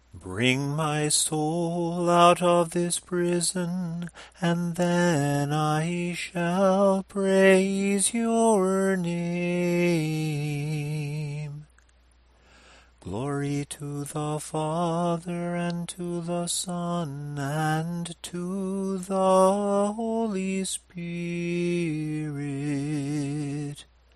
It starts on the ending note of the sticheron melody, and has the same "falling" conclusion.
Tone_2_samohlasen_verses.mp3